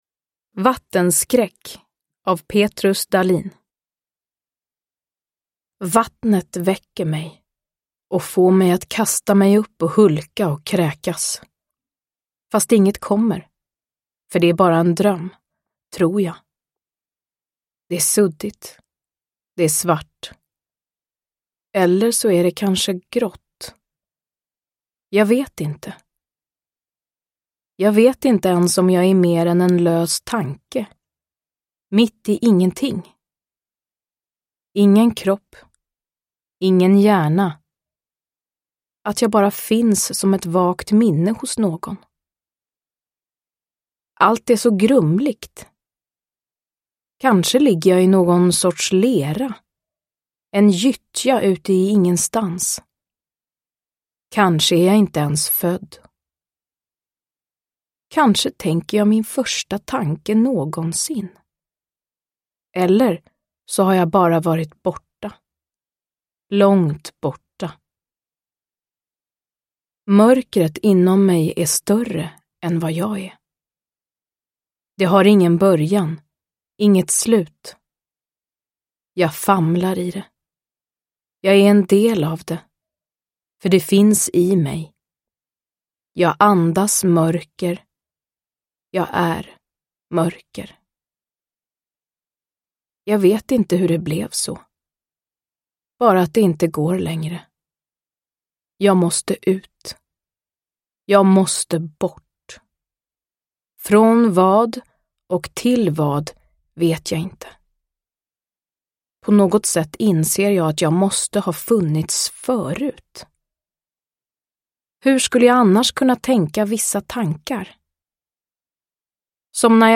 Vattenskräck – Ljudbok – Laddas ner
Uppläsare: Frida Hallgren